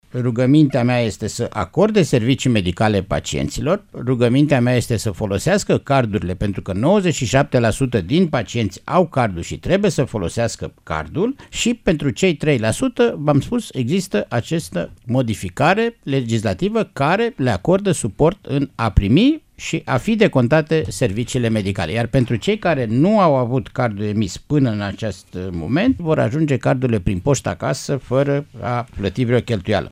Vasile Ciurchea i-a rugat astăzi pe medicii de familie, în emisiunea Probleme la zi să acorde servicii medicale pacienţilor care le solicită indiferent dacă au sau nu card de sănătate.